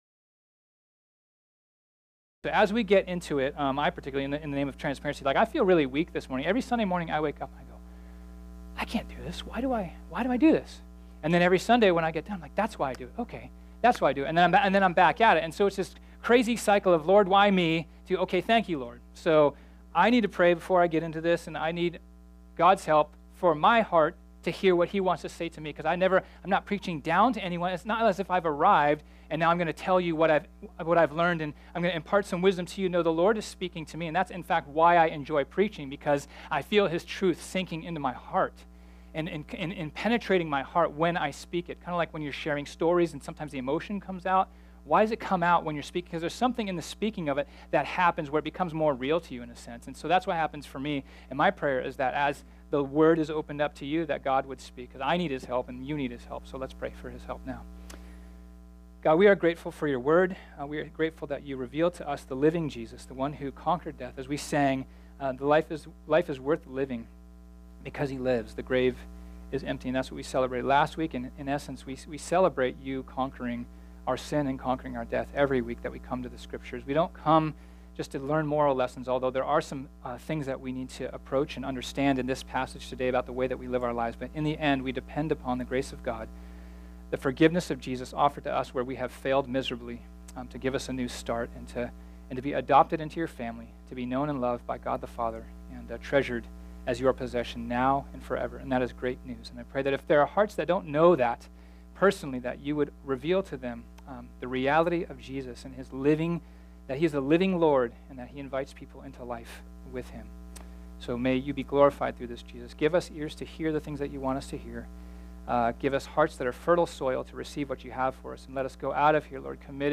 This sermon was originally preached on Sunday, April 8, 2018.